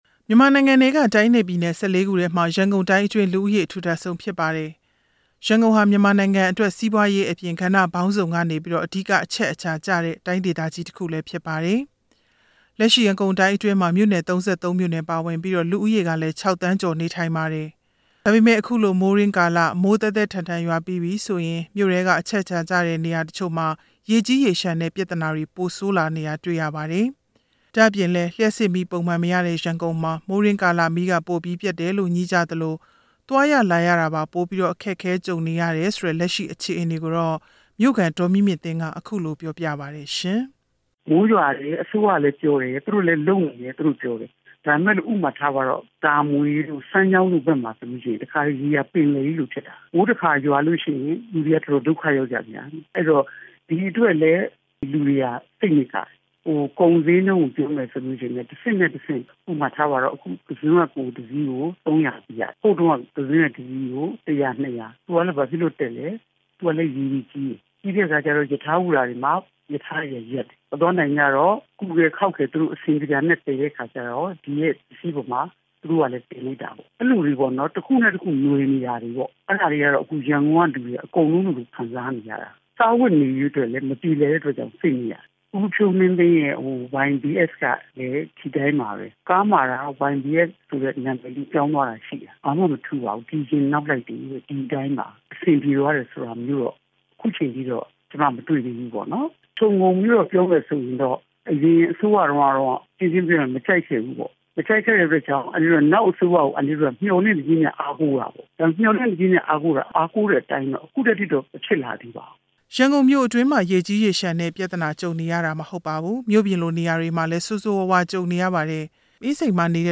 ရန်ကုန်မြို့ခံတချိုရဲ့ ရင်ဖွင့်သံ